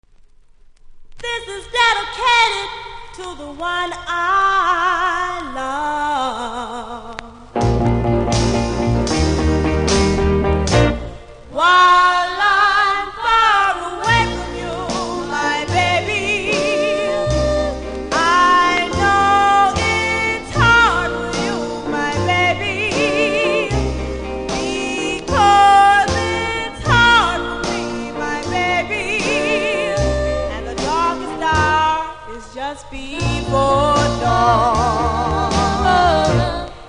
CONDITION：見た目NMですがプレス起因か少しノイズありますので試聴で確認下さい。